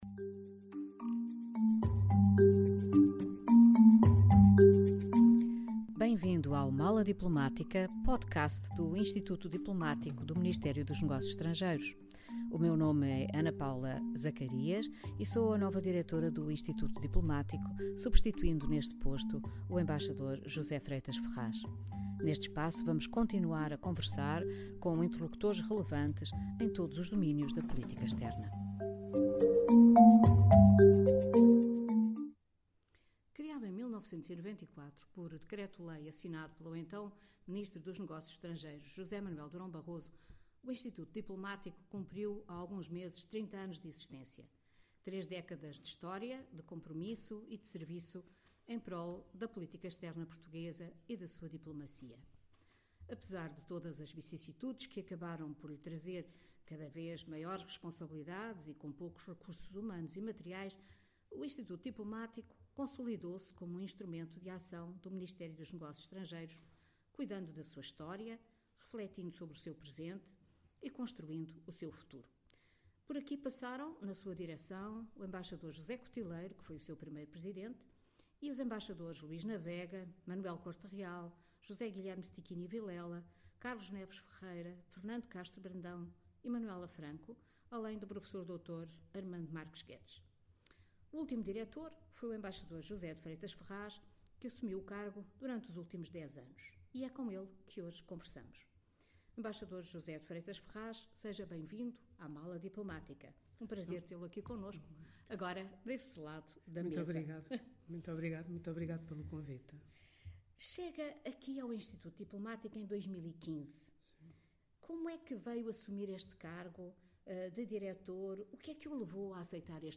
conversa com a Embaixadora Ana Paula Zacarias